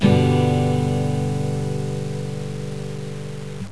virtual guitar